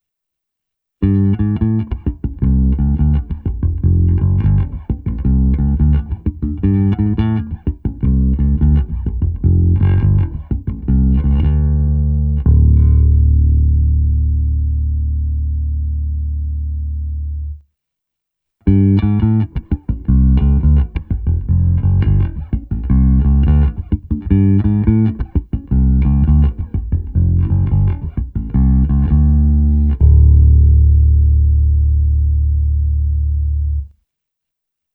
Ještě jsem pro zajímavost udělal nahrávku, kde v první části je zde recenzovaný Squier Vintage Modified Precision Bass V a v druhé pro porovnání můj Fender American Professional II Precision Bass V. Opět je to se simulací aparátu, ale nabíral jsem dvě cesty, je tam přimíchaný i zvuk prostý simulace.
Porovnávačka Squier vs. Fender